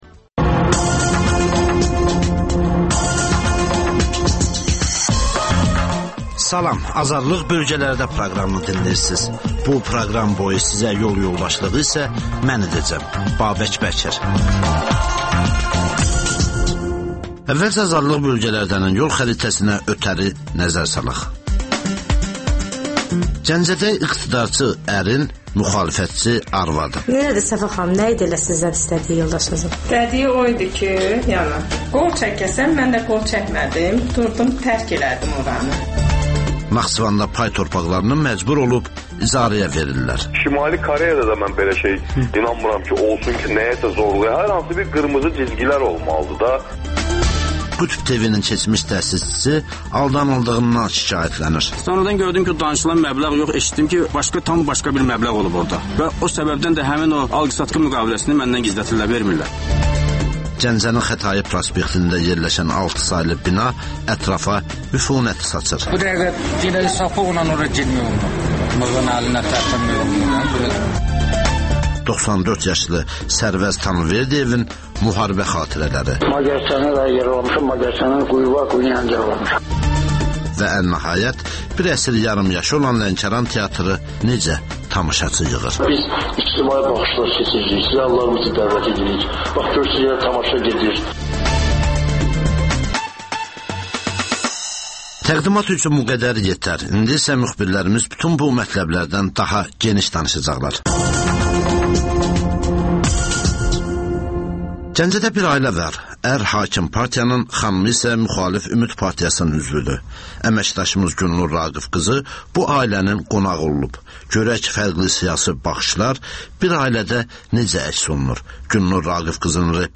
Rayonlardan xüsusi reportajlar